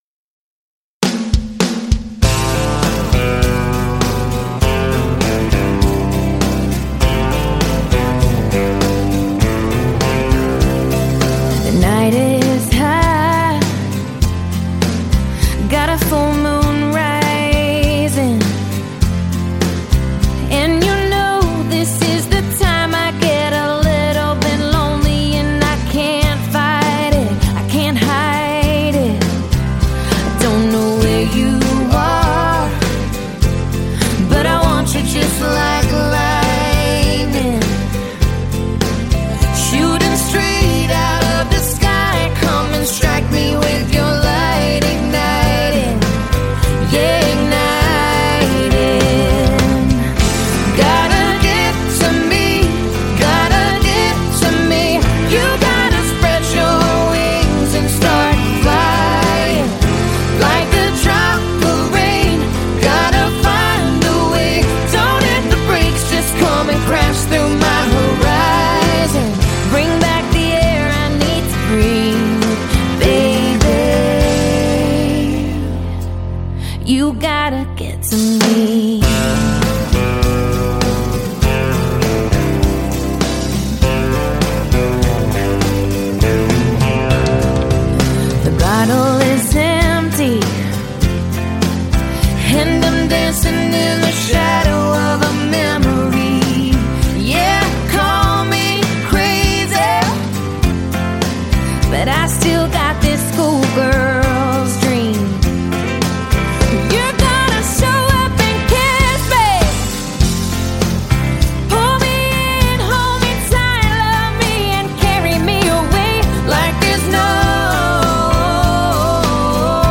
Жанр: Folk